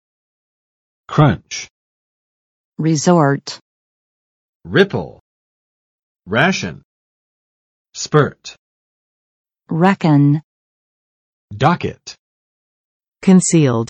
[krʌntʃ] n. 短缺; 危机
crunch.mp3